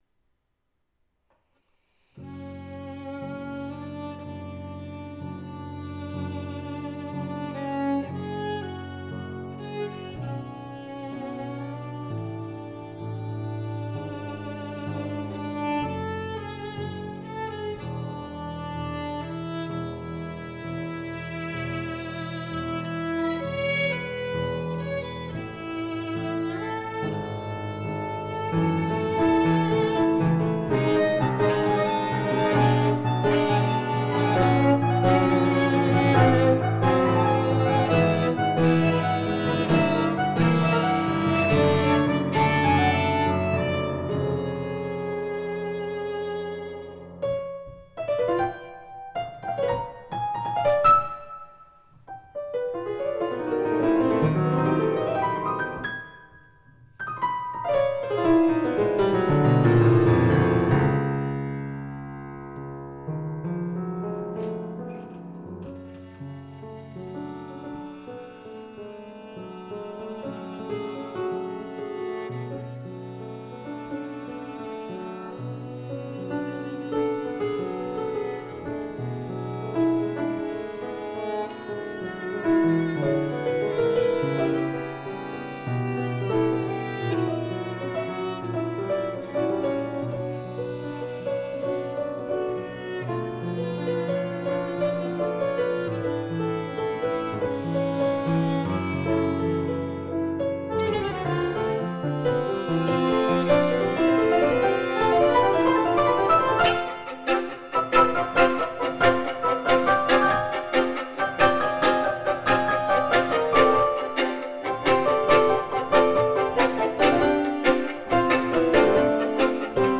Violino, Fisarmonica e Pianoforte